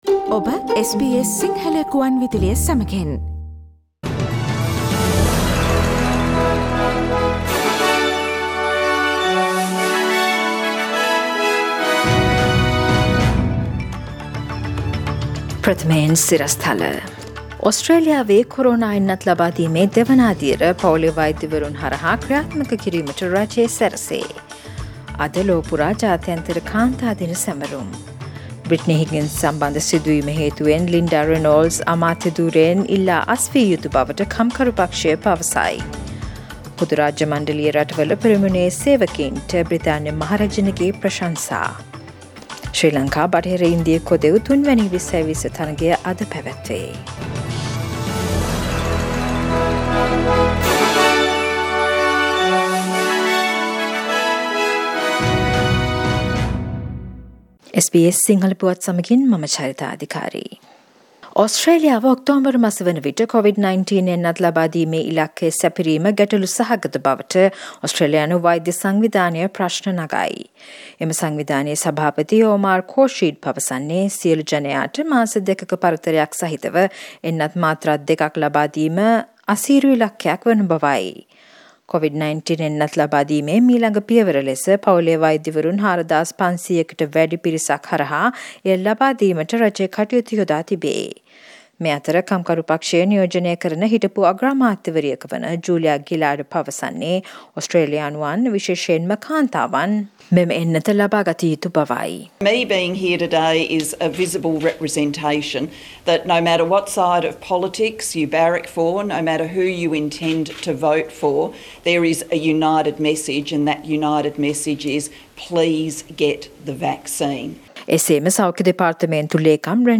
The most prominent news highlights from SBS Sinhala radio daily news bulletin on Monday 8 March 2021.